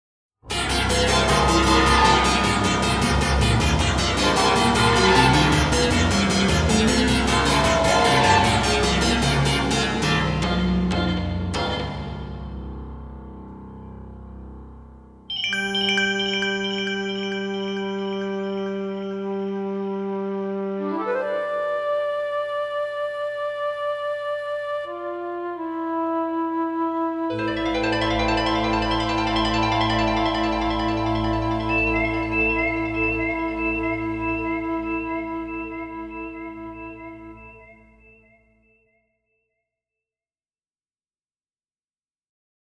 Orchestral Film Version